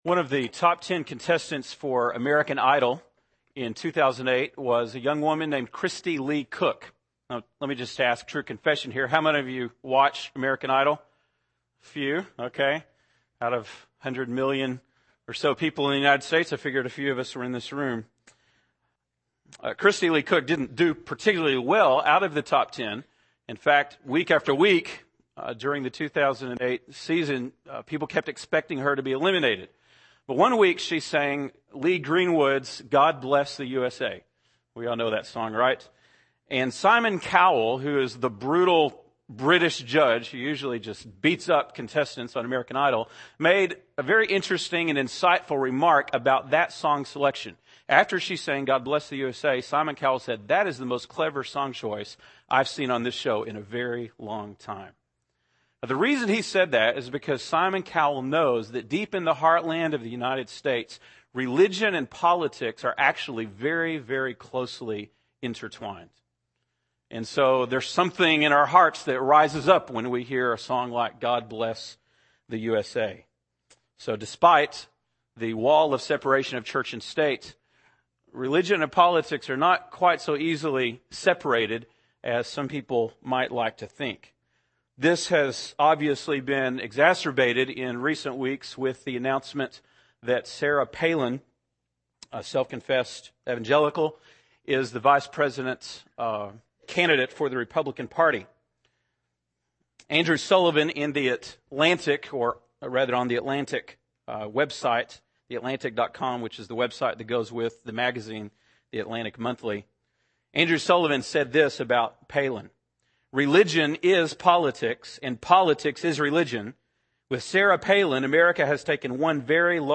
September 21, 2008 (Sunday Morning)